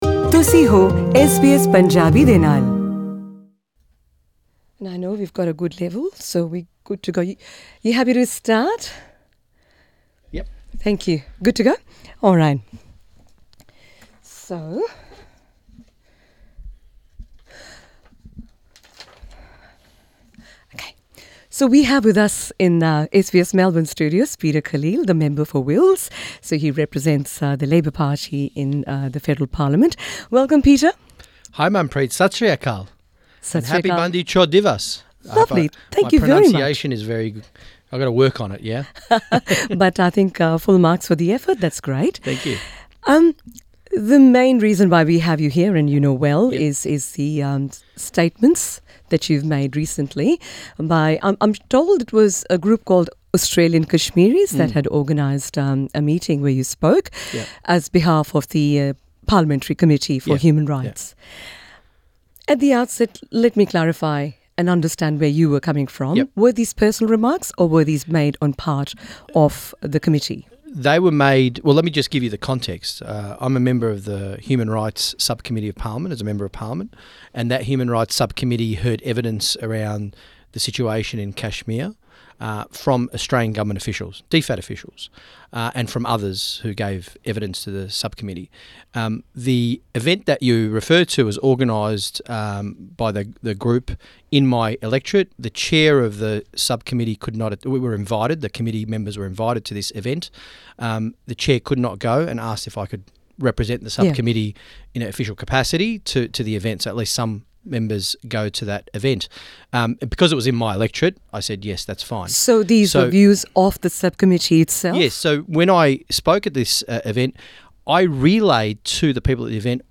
ALP's federal Member for Wills, Peter Khalil has been criticised in recent days for his comments at a recent Melbourne event about the current situation in Kashmir. Speaking to SBS Punjabi about this, Mr Khalil has described himself as 'a friend of India', insisting that he had 'nothing to apologise for,' since he only relayed information he had gathered at parliamentary briefings.
Peter Khalil MP, at SBS Melbourne Source: SBS Punjabi